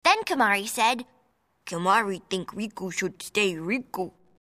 Voice 2 -
Sex: Female